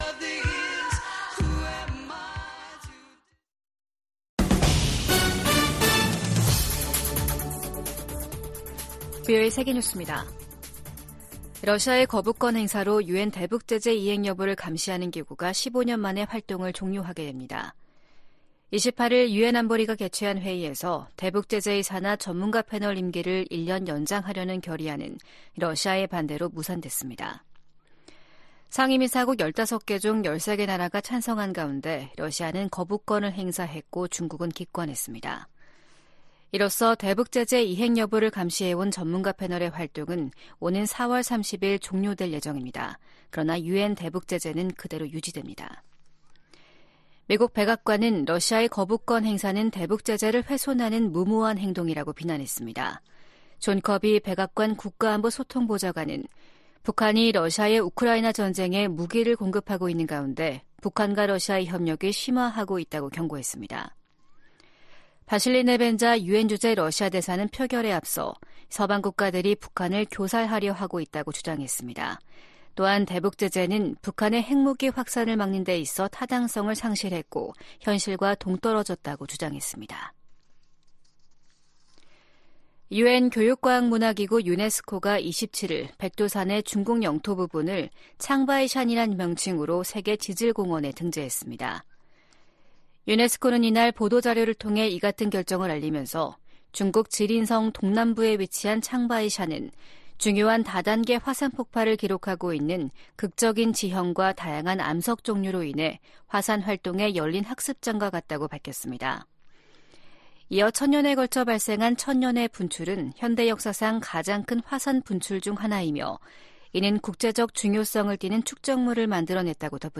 VOA 한국어 아침 뉴스 프로그램 '워싱턴 뉴스 광장' 2024년 3월 29일 방송입니다. 미국과 한국이 공동으로 북한 국적자 6명과 외국업체 2곳에 대한 제재를 단행했습니다. 북한이 최근 원심분리기 시설을 확장하고 있는 것으로 보인다는 보도가 나온 가운데 미국 정부는 위험 감소 등 북한과 논의할 것이 많다는 입장을 밝혔습니다. 중국이 미일 동맹 격상 움직임에 관해, 국가 간 군사협력이 제3자를 표적으로 삼아선 안 된다고 밝혔습니다.